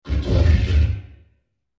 These were made using the vocoder in FL studio, and edited again in Audacity.
A bit fuzzy, but they can be very useful for campaign-mods.